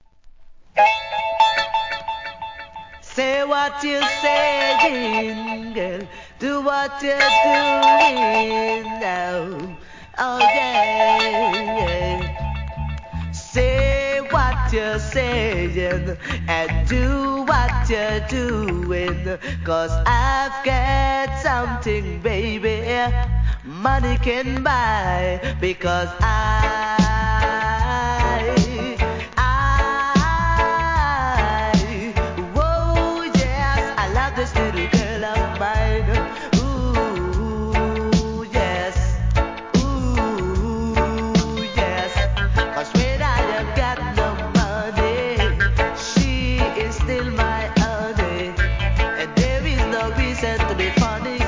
REGGAE
DANCEHALL CLASSIC!!